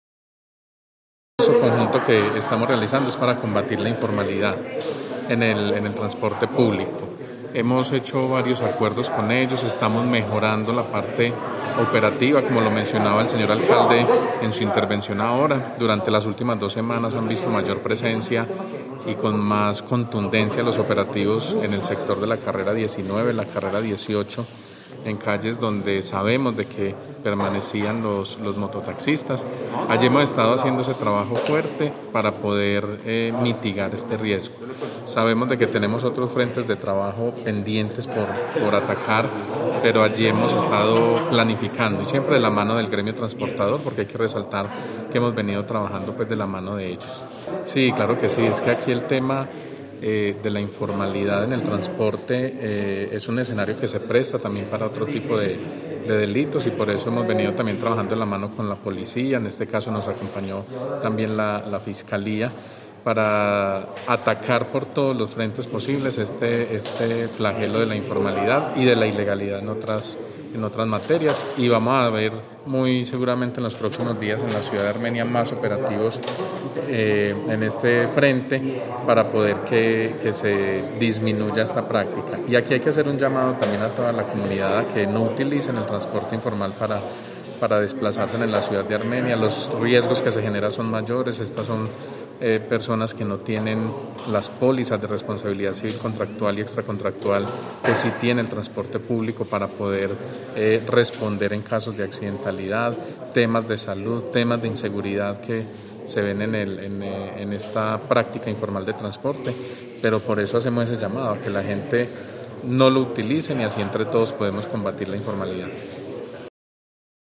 Audio: Daniel Jaime Castaño – secretario de Tránsito y Transporte de Armenia